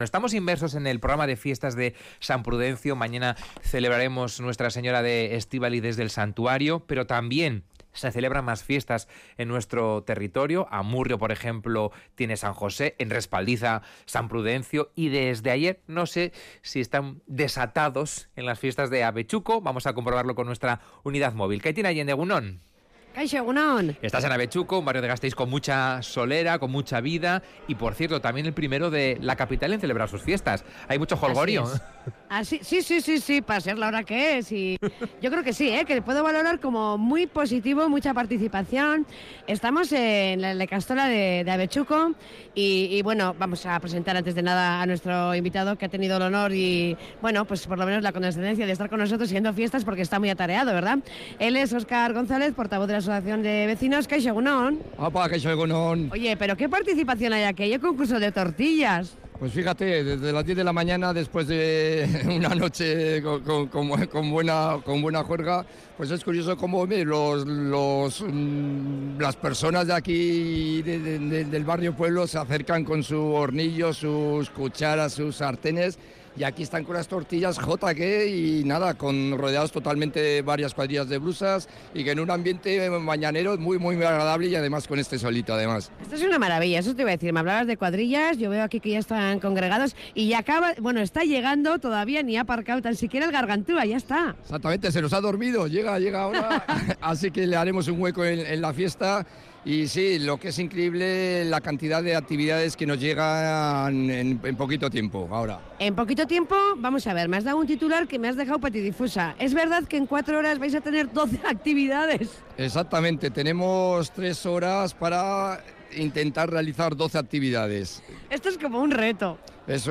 Audio: La unidad móvil se ha desplazado a Abetxuko para vivir en directo las primeras fiestas del año. En cuatro horas, más de 12 actividades.